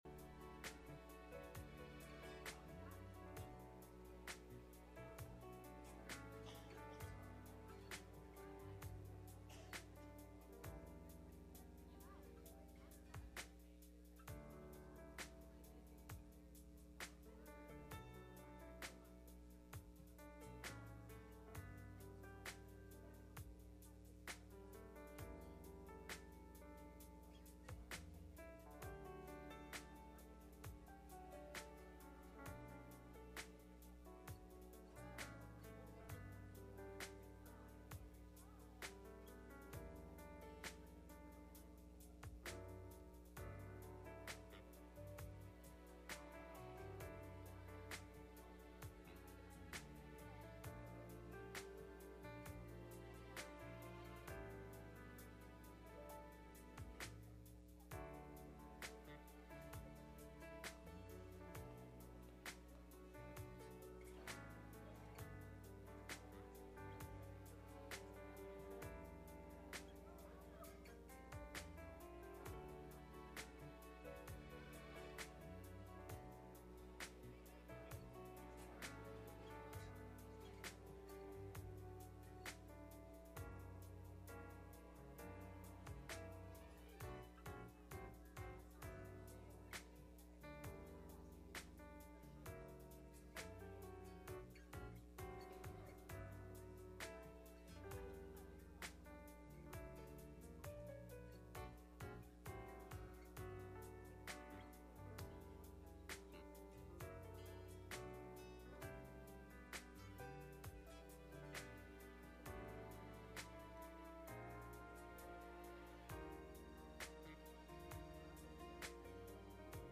Wednesday Night Service
Midweek Meeting